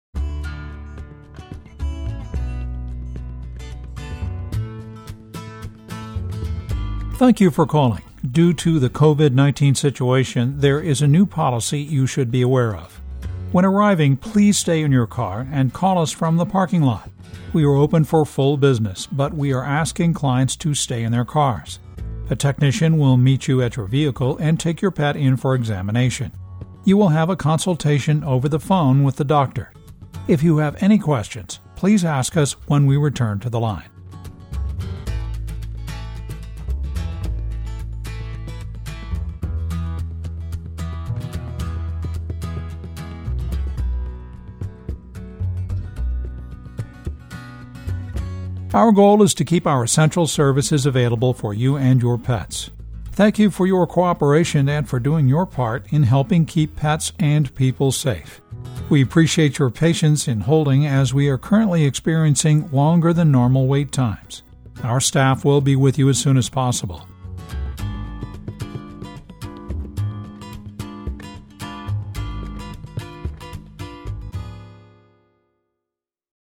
MOH Sample